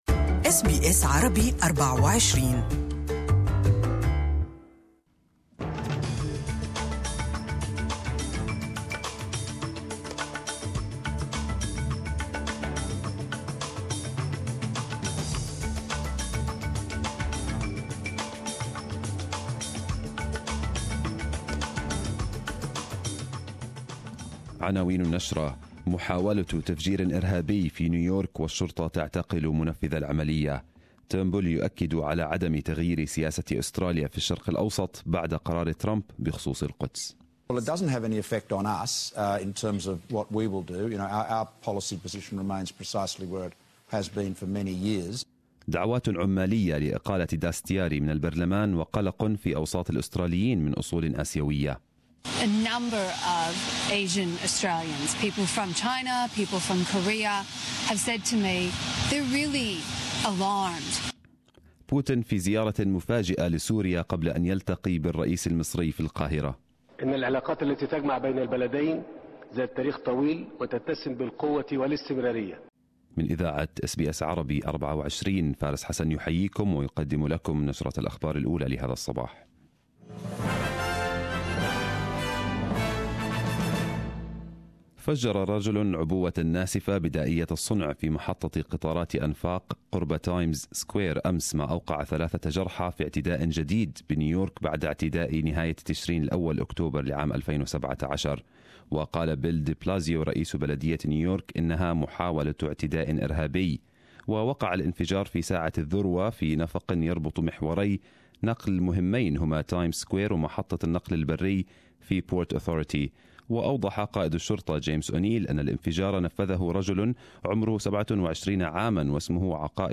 Arabic News Bulletin 12/12/2017